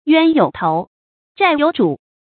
冤有頭，債有主 注音： ㄧㄨㄢ ㄧㄡˇ ㄊㄡˊ ，ㄓㄞˋ ㄧㄡˇ ㄓㄨˇ 讀音讀法： 意思解釋： 冤有冤頭，債有債主。